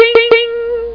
1 channel
TransferDenied.mp3